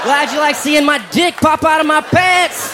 Category: Comedians   Right: Both Personal and Commercial
Tags: aziz ansari aziz ansari comedian